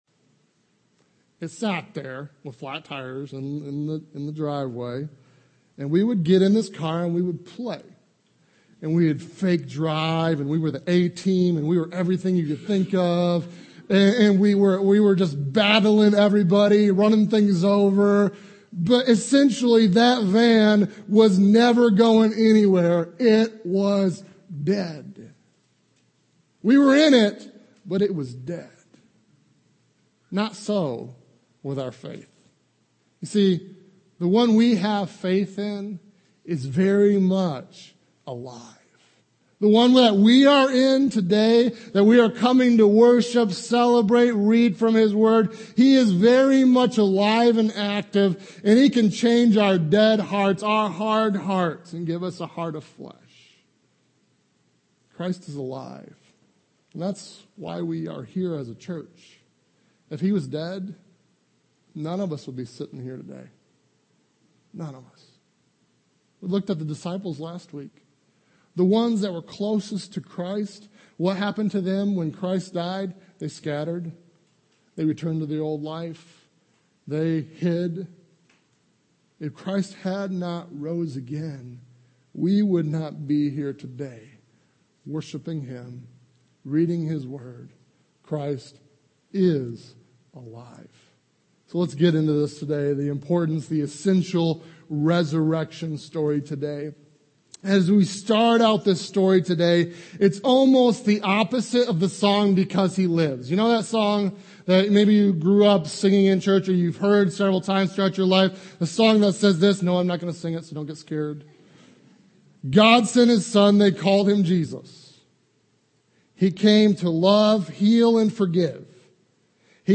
Rossville Bible Fellowship Sermons